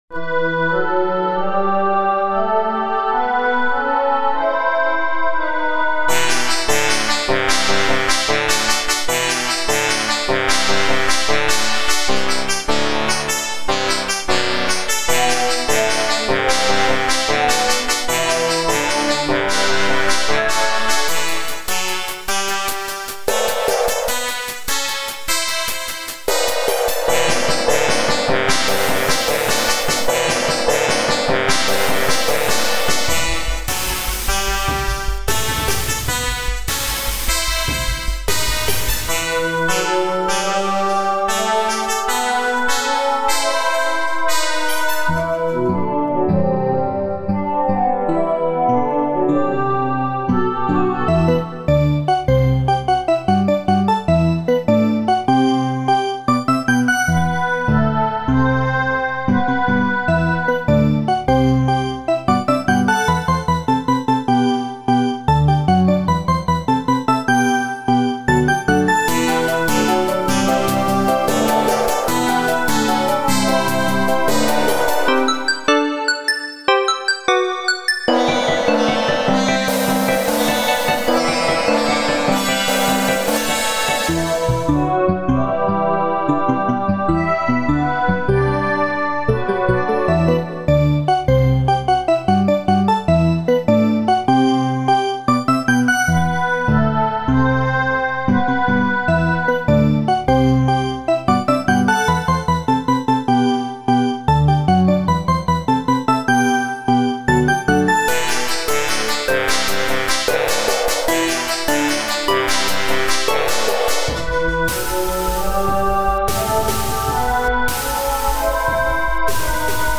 This composition takes its name from the 15-beat structure that repeats throughout.
I was especially pleased, after considerable experimentation, having discovered a waveform that (somewhat) mimics a human voice chorus. You will often hear them singing just four notes, with durations 3,3,5,4.